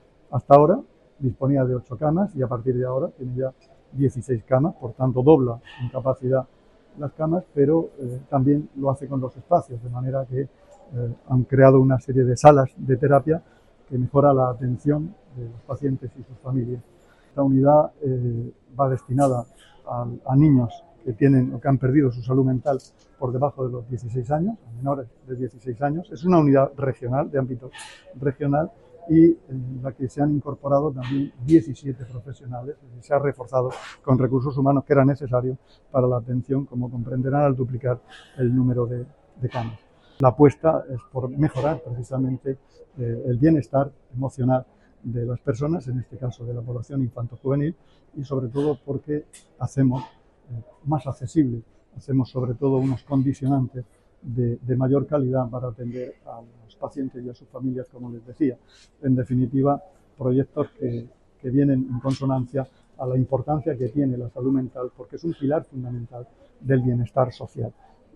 Sonido/ Declaraciones del consejero de Salud sobre la ampliación de la Unidad de Psiquiatría Infanto-Juvenil del hospital Virgen de la Arrixaca.